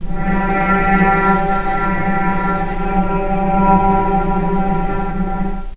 vc_sp-G3-pp.AIF